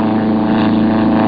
.1Biplane..mp3